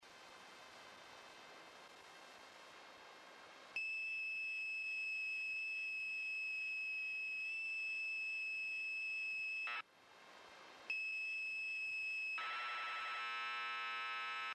La señal viene con algo de ruido.